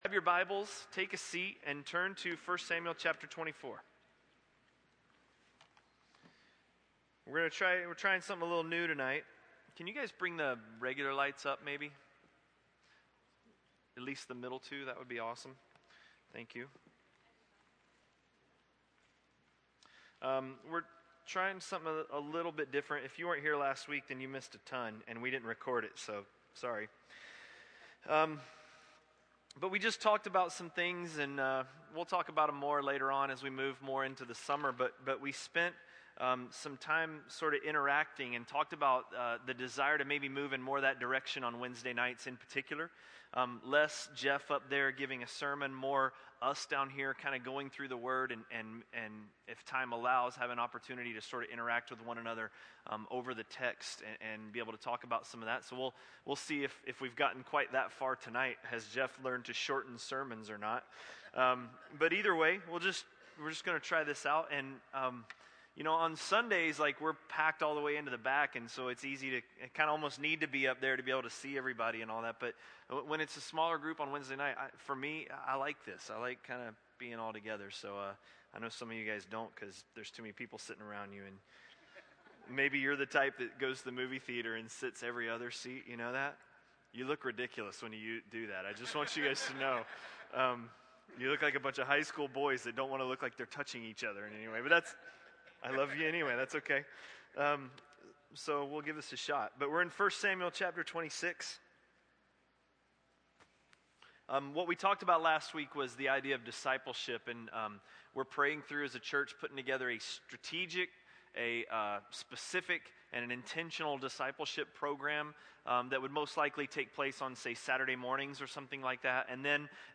A message from the series "1 Samuel." 1 Samuel 26